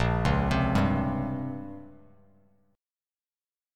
A#m6add9 chord